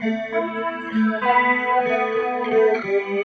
43 GUIT 3 -R.wav